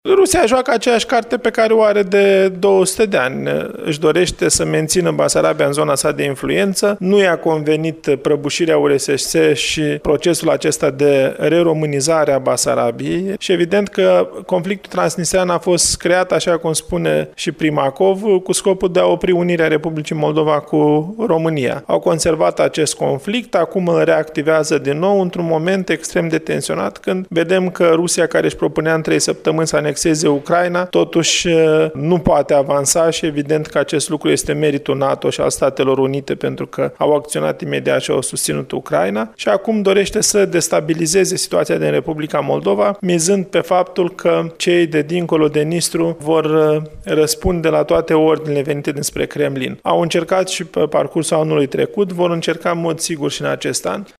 Pe de altă parte, Rusia a reactivat conflictul din Transnistria, a afirmat, la Iași, europarlamentarul Eugen TOMAC.